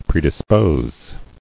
[pre·dis·pose]